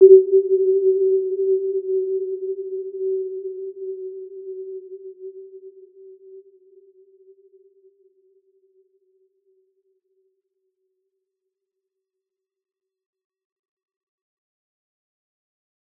Warm-Bounce-G4-mf.wav